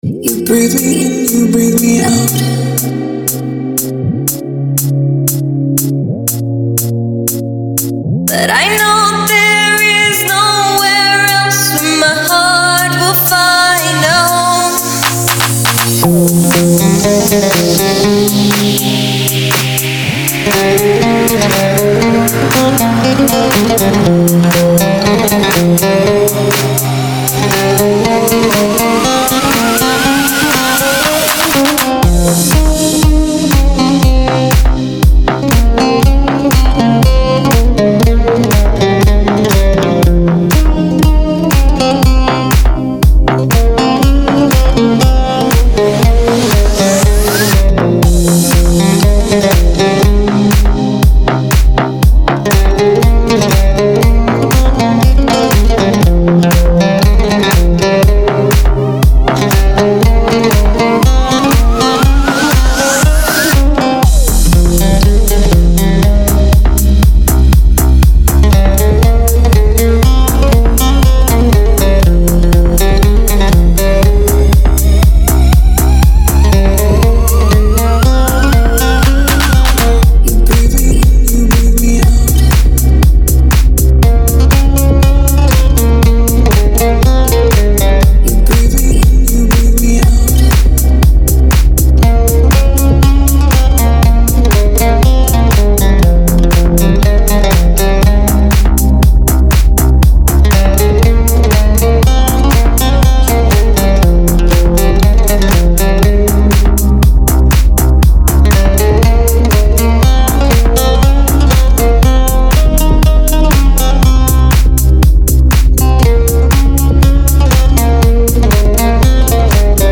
это энергичная композиция в жанре электронной музыки